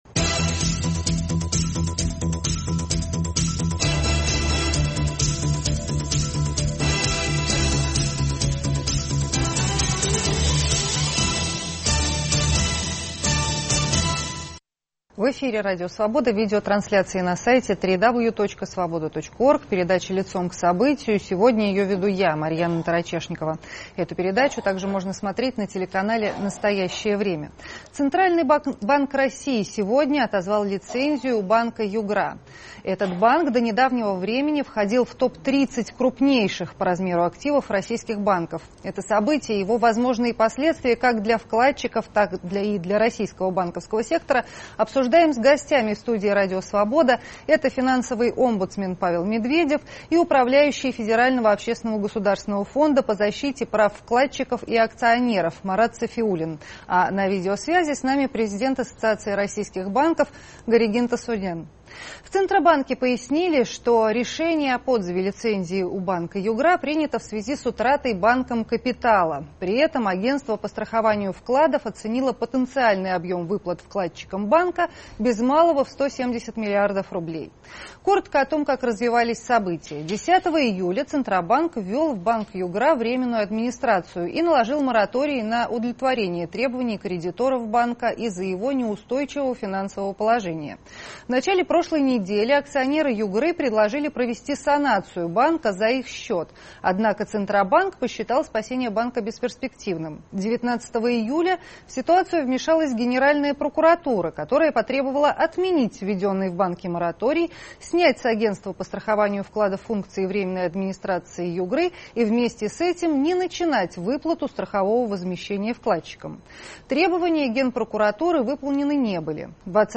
Это событие и его возможные последствия, как для вкладчиков, так и для российского банковского сектора обсуждаем с гостями в студии Радио Свобода